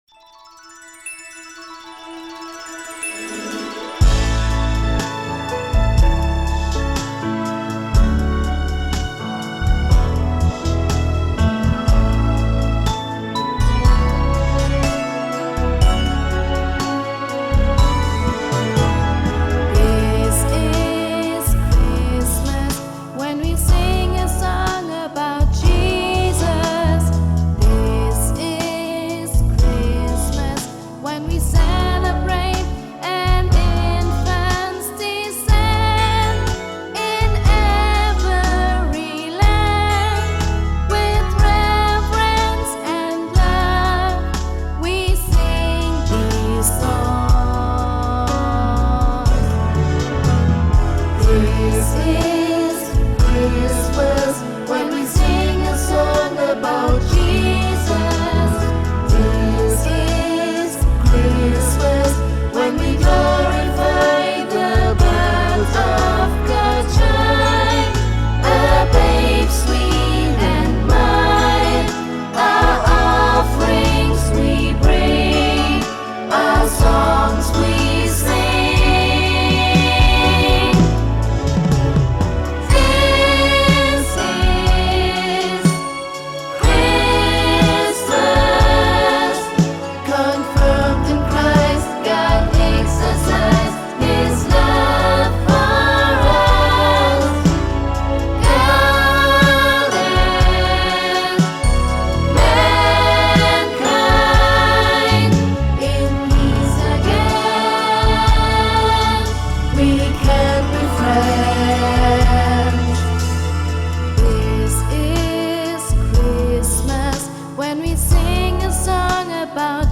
SAT(B), Soloist + Piano